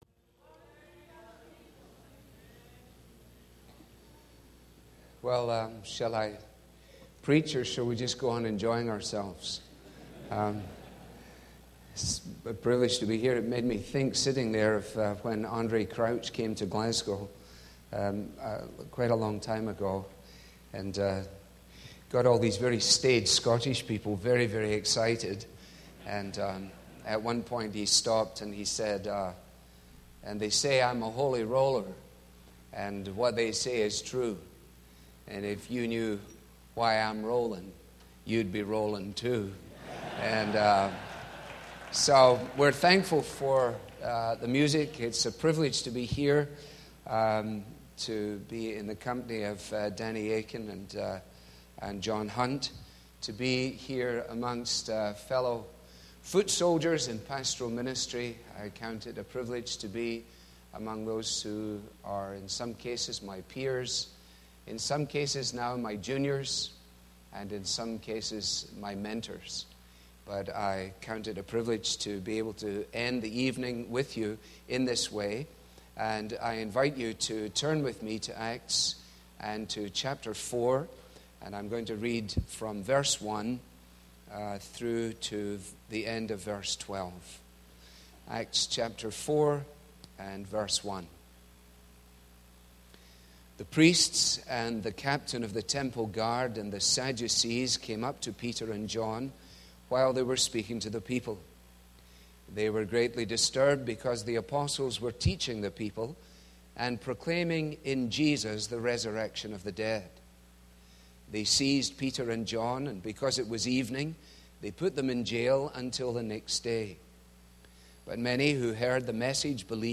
About the 2010 Kentucky Baptist Pastor's Conference: Alistair Begg, pastor of Parkside Church near Cleveland, Ohio, who hosts the daily radio program, “Truth For Life,” preached from Acts 4 which records the imprisonment of John and Peter after the healing of crippled man in Jerusalem.
Allistair_Begg_KY_Bapt_Convention_PART_1.mp3